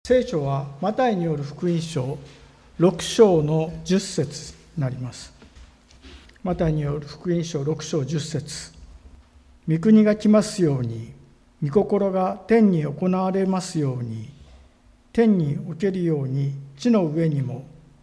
【聖書箇所朗読】